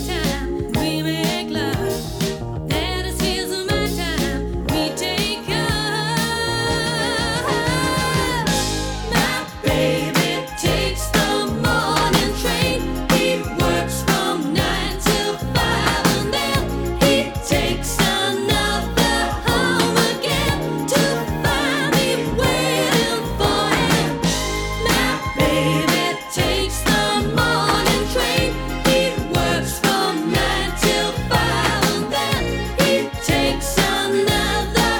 Жанр: Поп музыка / Рок / Танцевальные